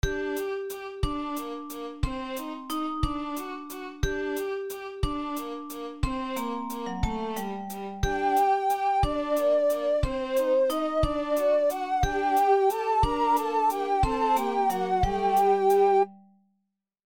Just some practice, nothing much.